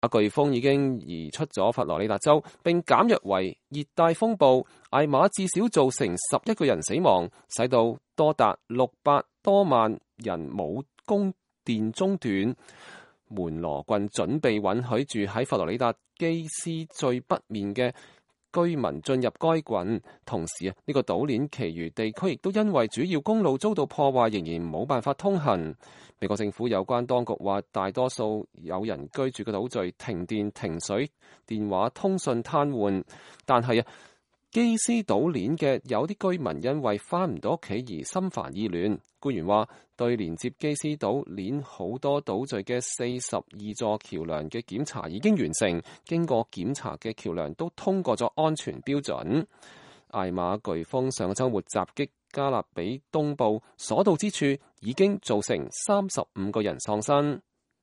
以下是一段警察跟基斯島鏈上拉戈島居民的對話：